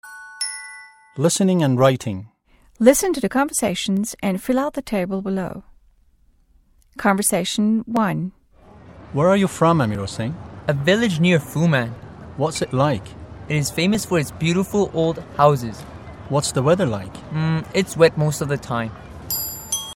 مکالمه ی اول - متن لیسنینگ listening هشتم